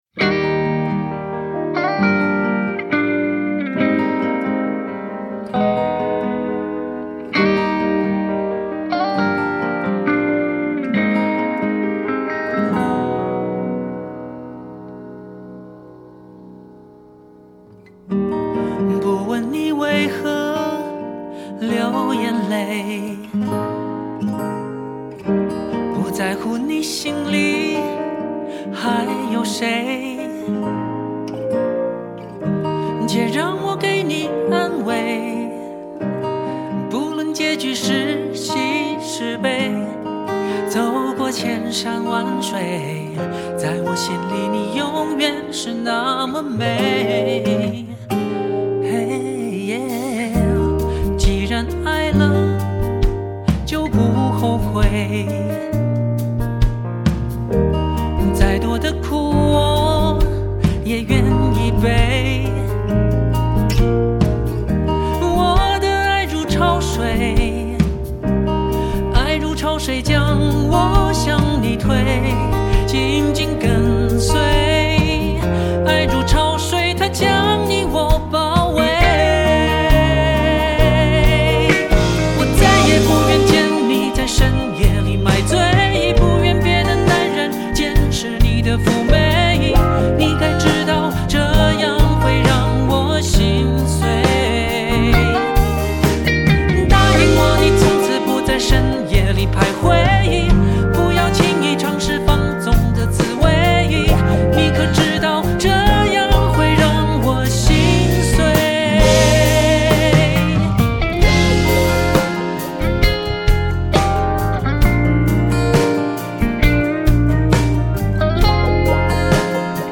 ---录音室母带16/44.1，以Weiss Saracon升频24/96